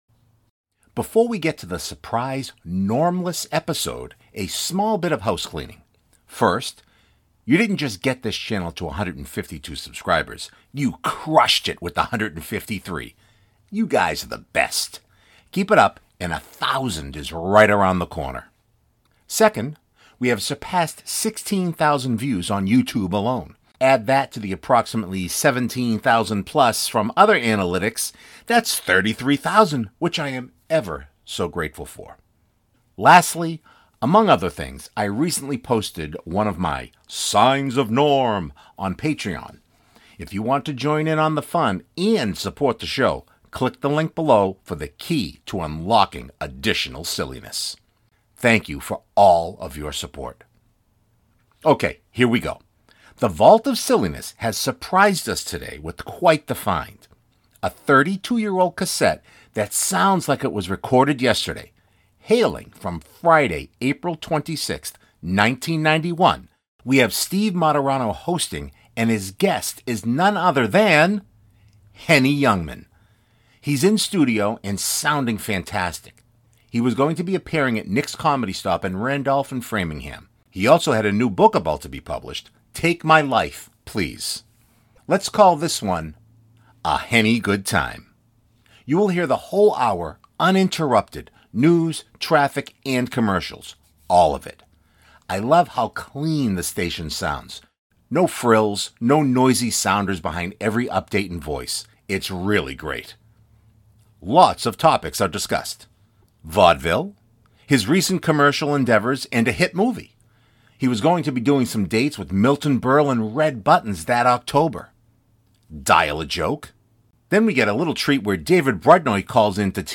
A 32-year-old cassette that sounds like it was recorded yesterday.
News, traffic, and commercials.
No frills, no noisy sounders behind every update and voice.